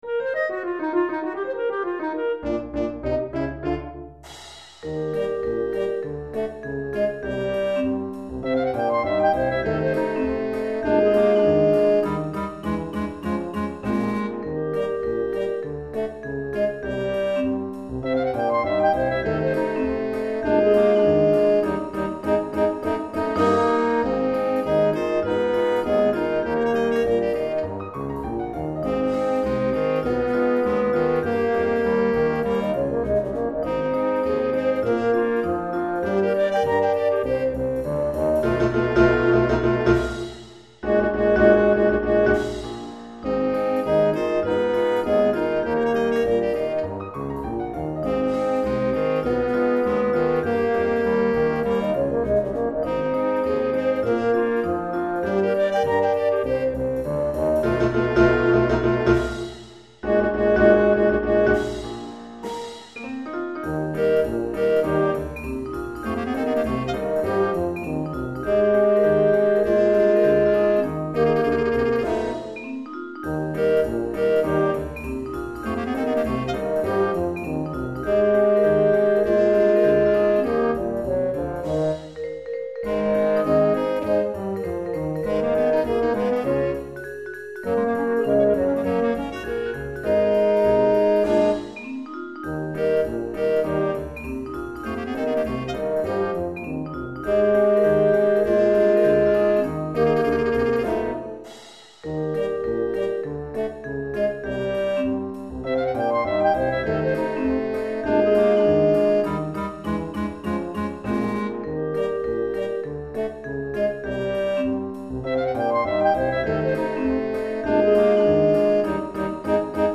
Chorale d'Enfants Clarinettes (2 Partitions) Tromp